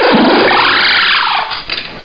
cry_not_klinklang.aif